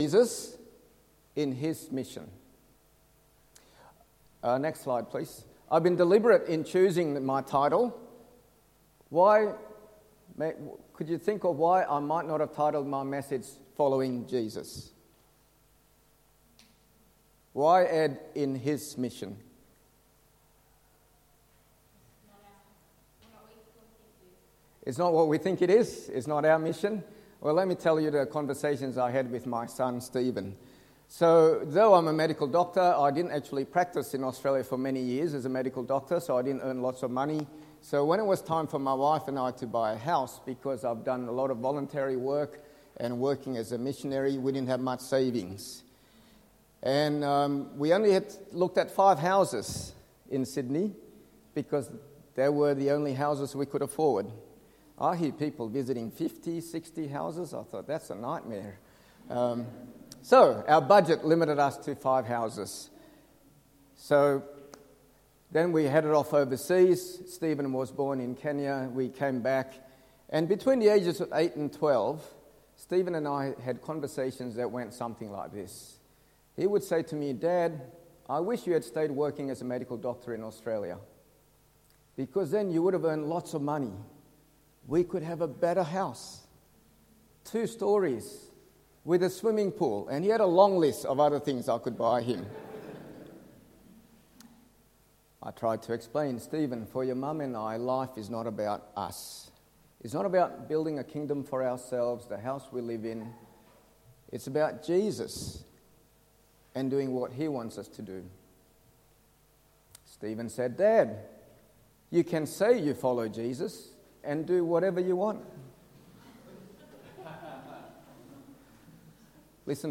SIM Directo Text: Mark 1:16-18 Sermon